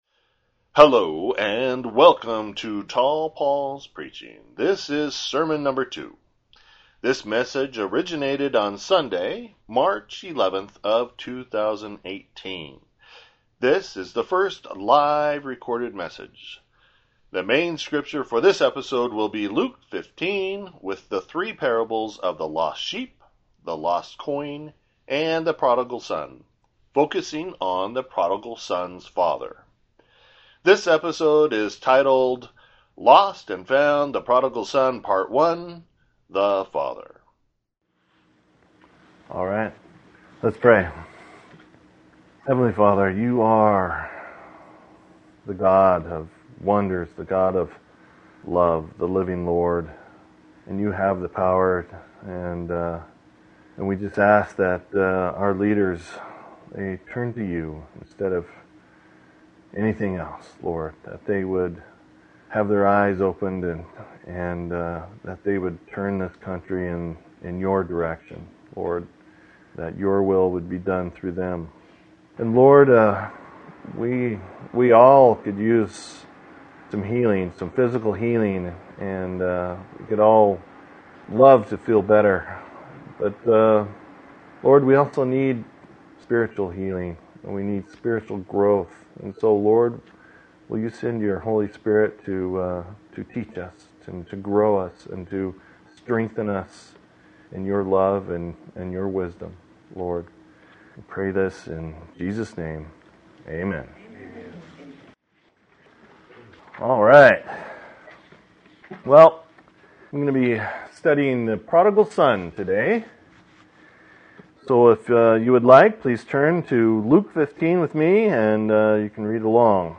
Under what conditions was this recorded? This is the first live recorded message.